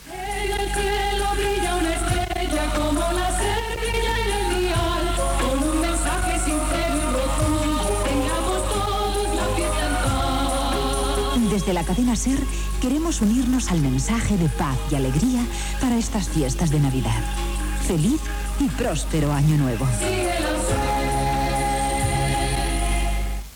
Felicitació nadalenca amb la cançó